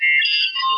Computer4.wav